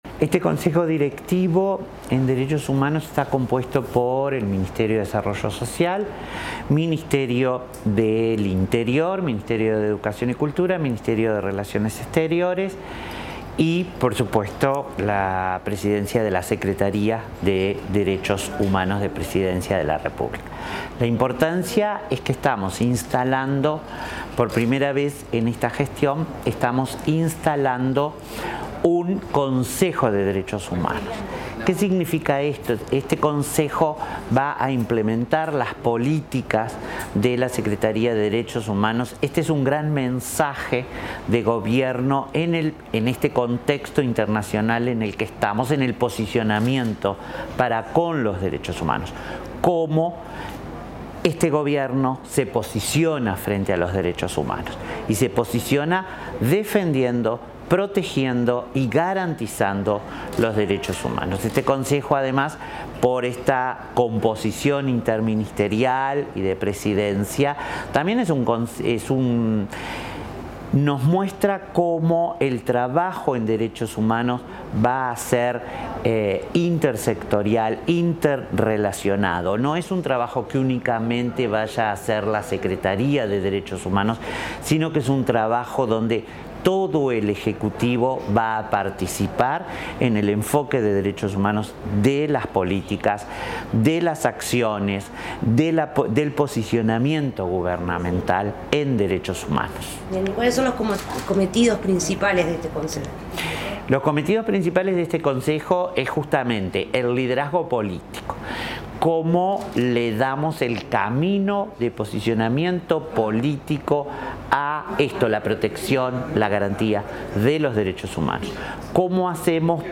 Declaraciones de la secretaria de Derechos Humanos, Collette Spinetti